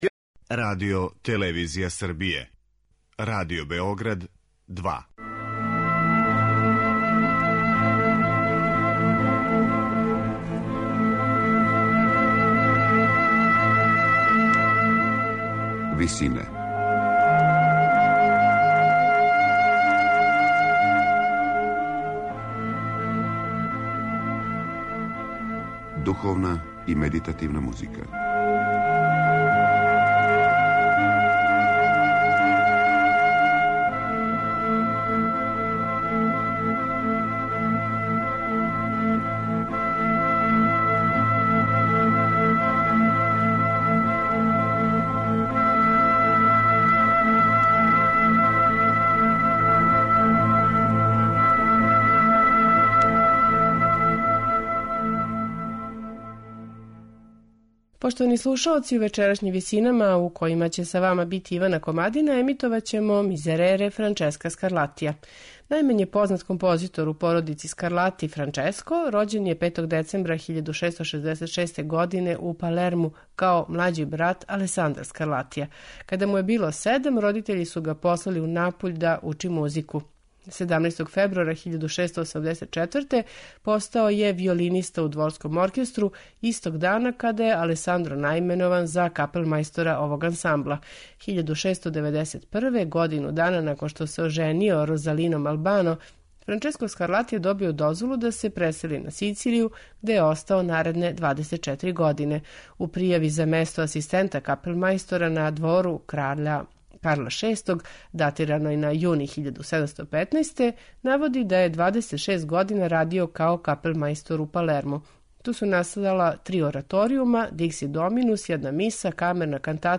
Као и друга сачувана Скарлатијева дела тог жанра, и Мизерере је писан у монументалној римској традцији, са великим композиционим умећем.